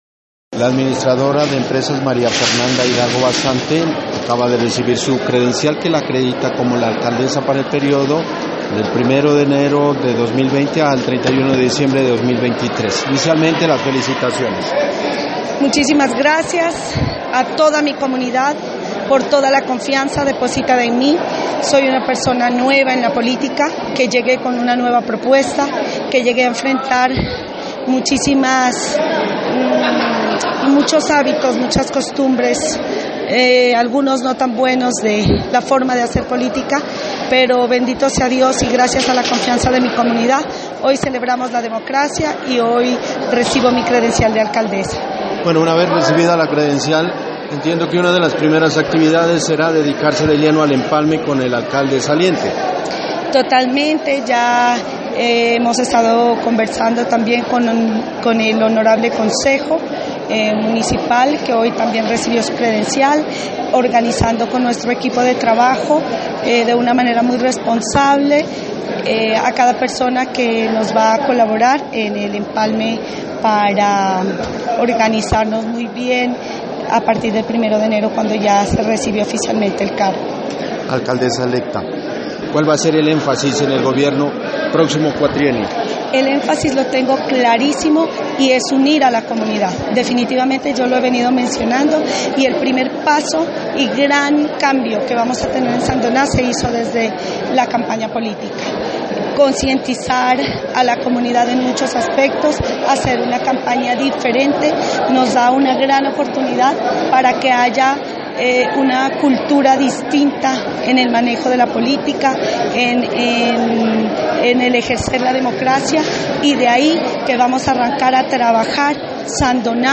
En entrevista que realizamos este miércoles expresó que “vamos a arrancar a trabajar a Sandoná como uno solo, sin divisiones, sin restricciones, sin hacer a un lado a las personas que no votaron o que votaron por el lado contrario, sino haciendo la unidad y dando un ejemplo de que en equipo, como lo hacemos en la parte privada, se puede y se logra todo.”